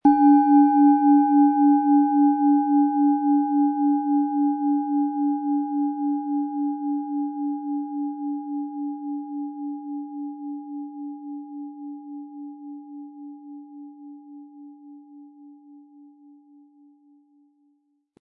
• Mittlerer Ton: Uranus
Spielen Sie die Mars mit dem beigelegten Klöppel sanft an, sie wird es Ihnen mit wohltuenden Klängen danken.
PlanetentöneMars & Uranus
SchalenformBihar
MaterialBronze